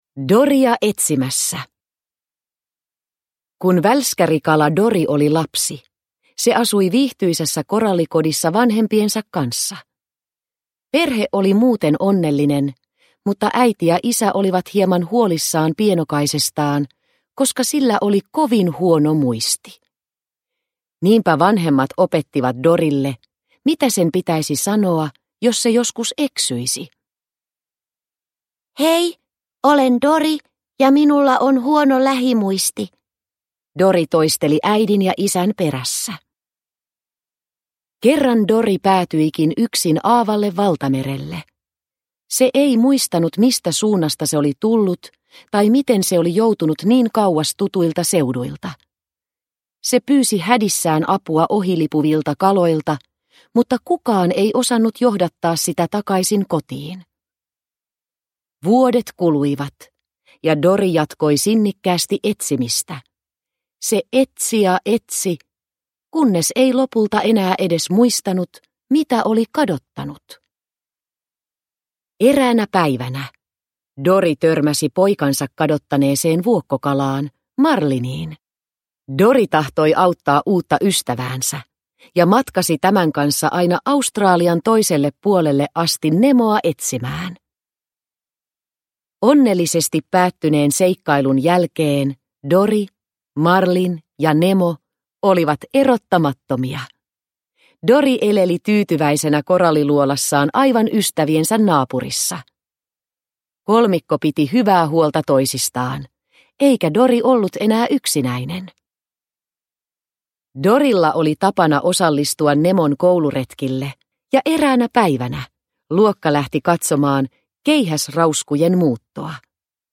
Doria etsimässä – Ljudbok – Laddas ner